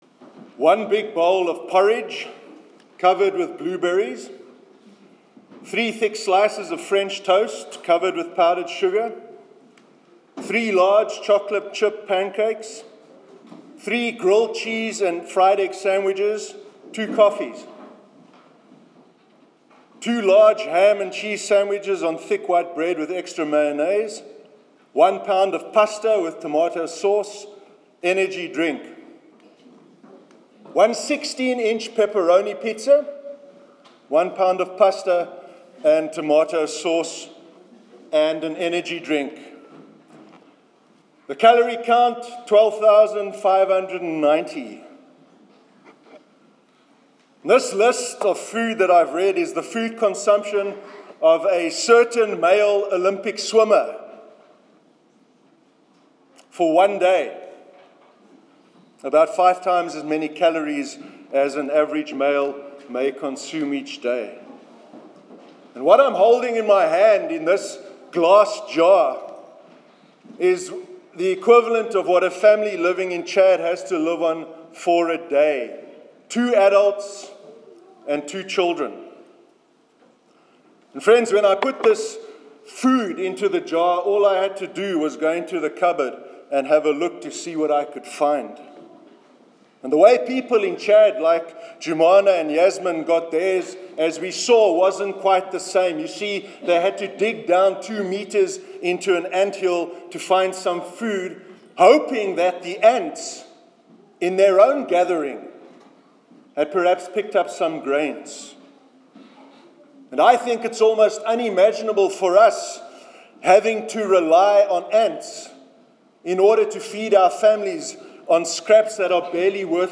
Church sermons